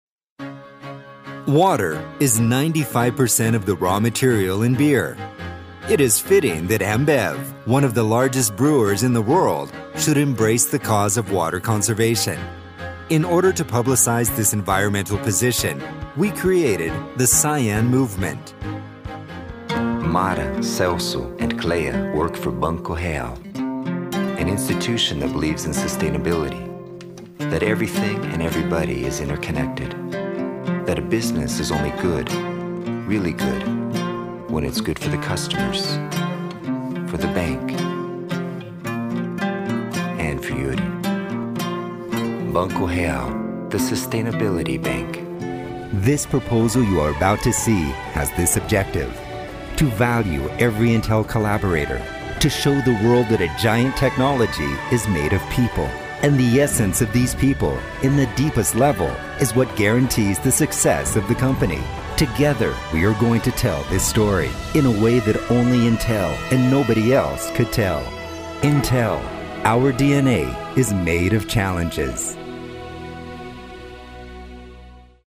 Friendly, honest, casual, American voice
Sprechprobe: Industrie (Muttersprache):